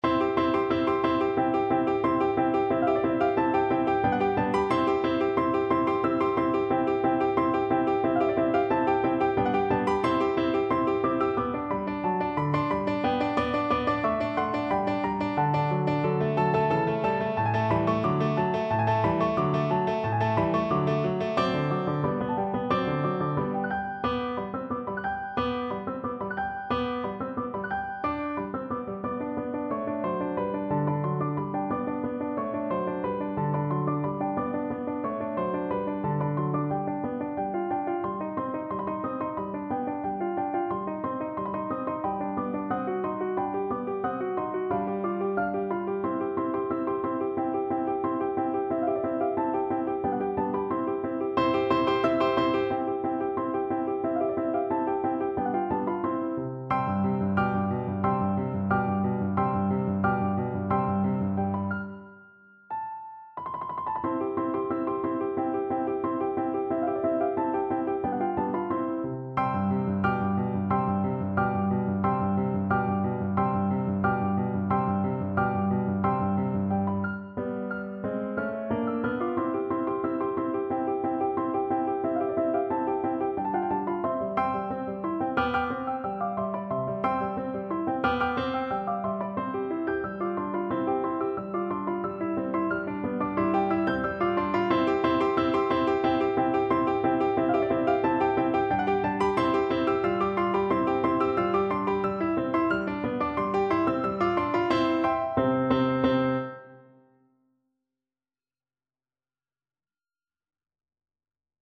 Play (or use space bar on your keyboard) Pause Music Playalong - Piano Accompaniment Playalong Band Accompaniment not yet available transpose reset tempo print settings full screen
C major (Sounding Pitch) (View more C major Music for Violin )
Presto =180 (View more music marked Presto)
Classical (View more Classical Violin Music)